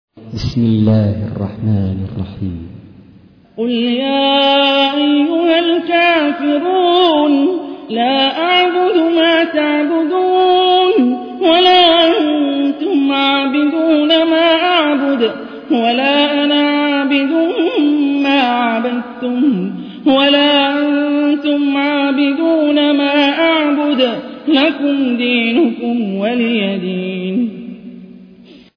تحميل : 109. سورة الكافرون / القارئ هاني الرفاعي / القرآن الكريم / موقع يا حسين